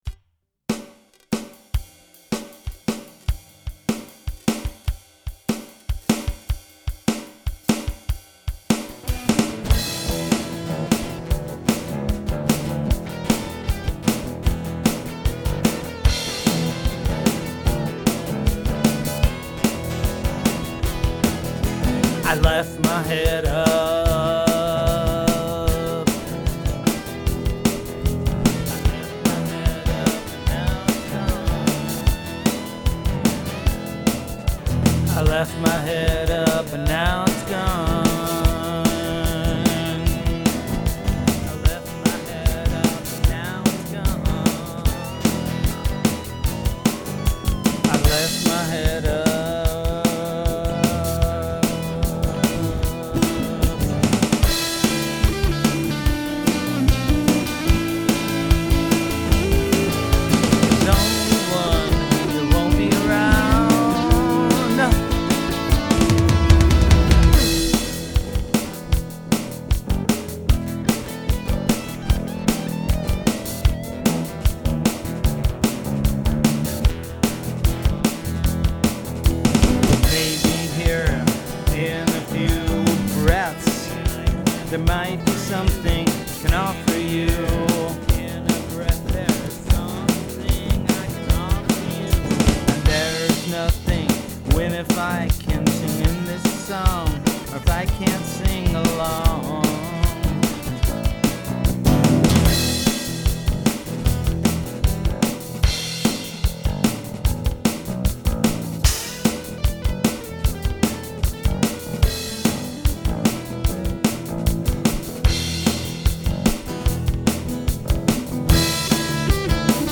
experimental project/LP